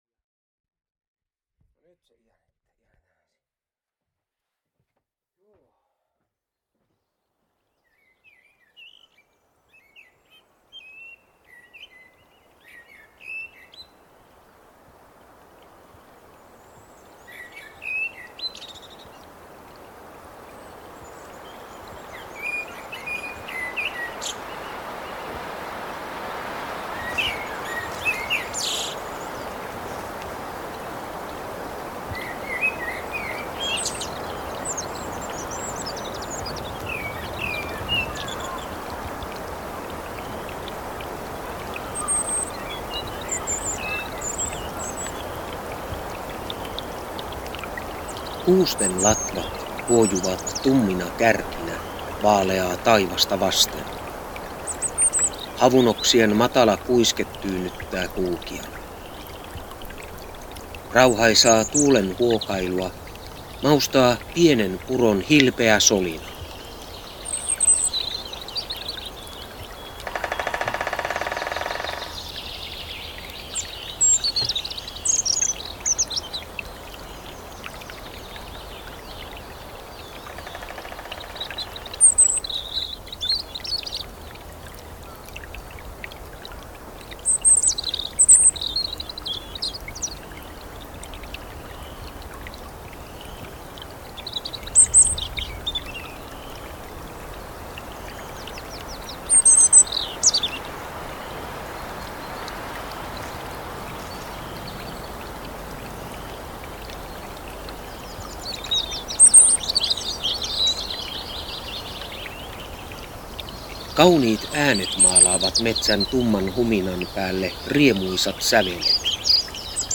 Rauhoittava linnunlaulu saa hyvälle mielelle.
kevatmetsa.mp3